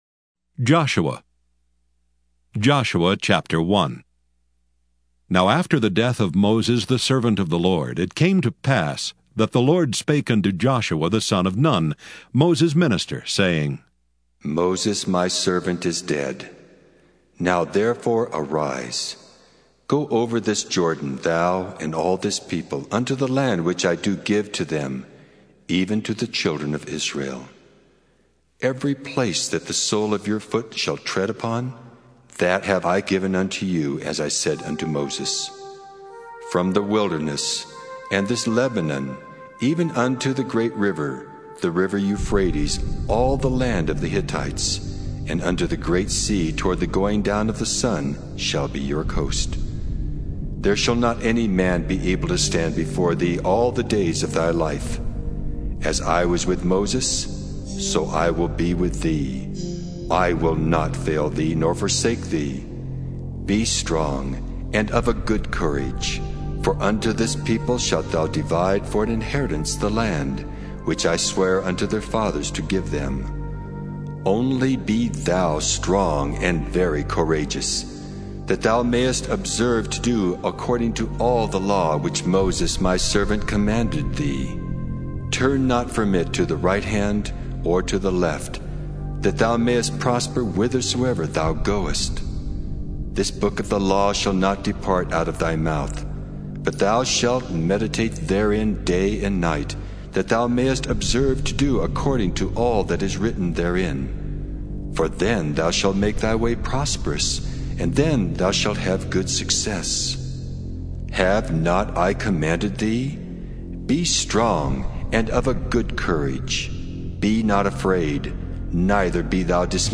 KJV MP3 Audio Bible, King James Version